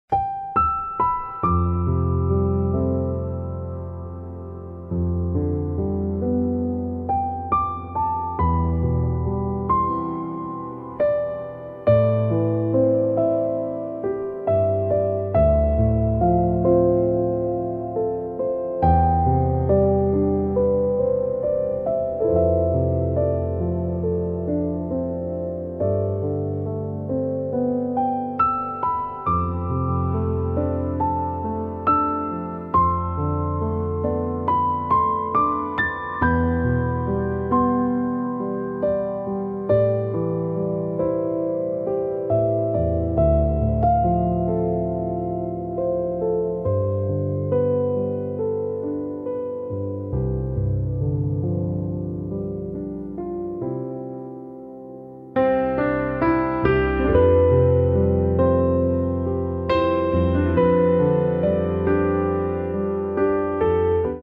Elegant Piano Music